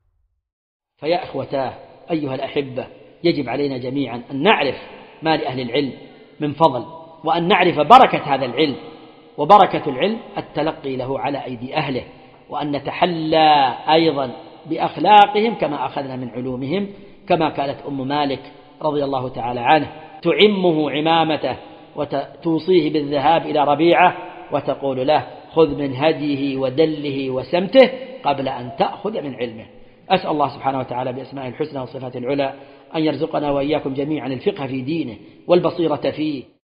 محاضرة بعنوان : ( أثر تحصيل العلم النافع في اندثار الفتن ).